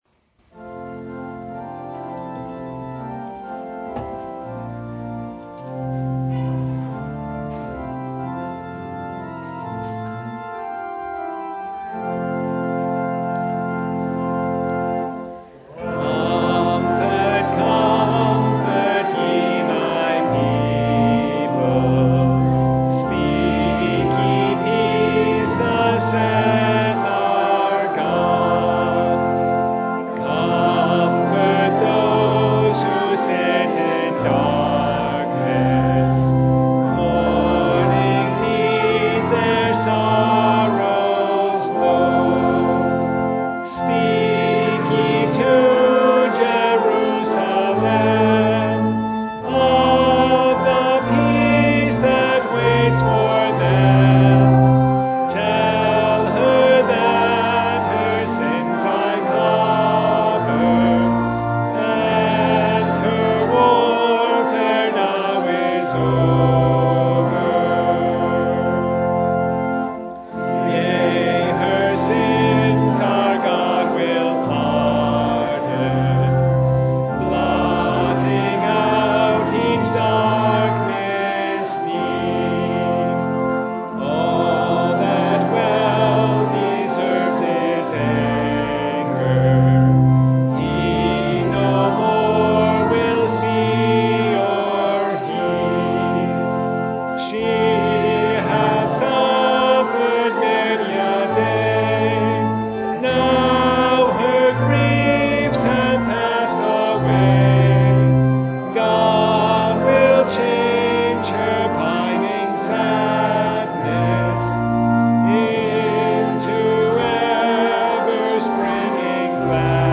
Service Type: Advent Vespers
Full Service